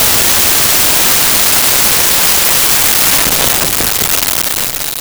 Metal Crash 1
Metal Crash_1.wav